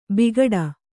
♪ bigaḍa